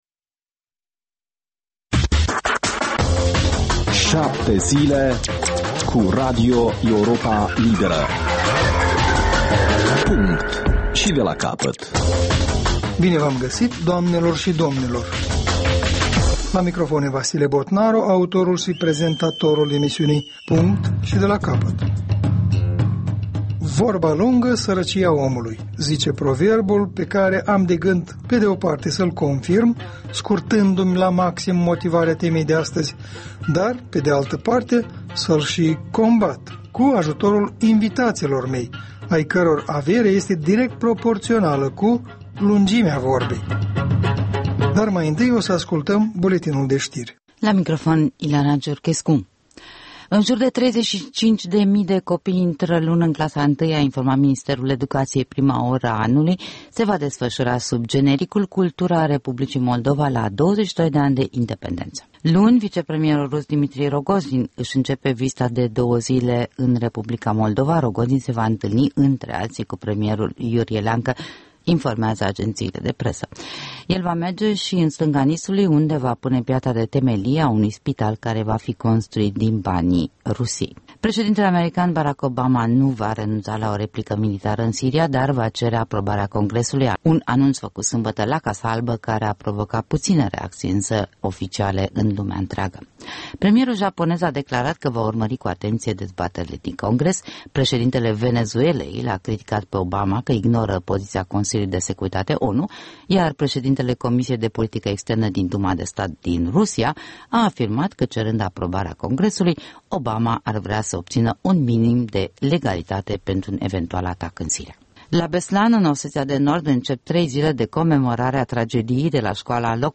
O discuţie la masa rotundă